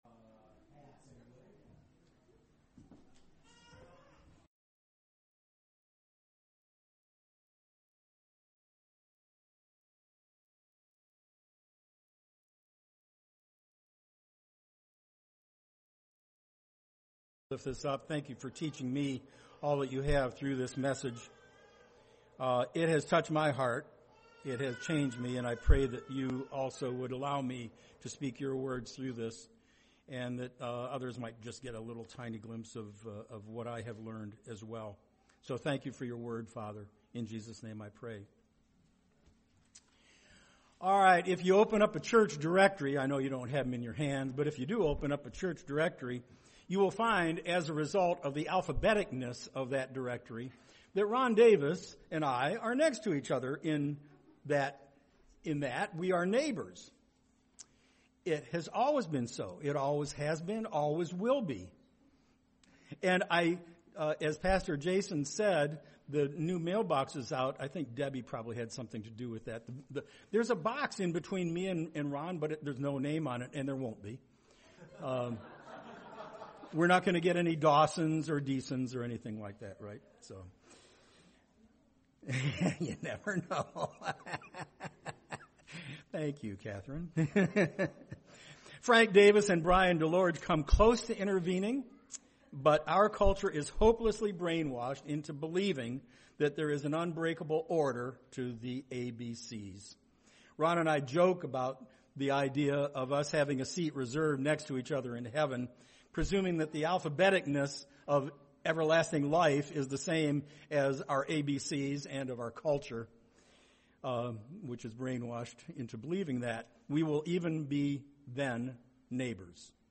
Sunday Morning Service Bible Text